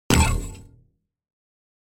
دانلود صدای ربات 66 از ساعد نیوز با لینک مستقیم و کیفیت بالا
جلوه های صوتی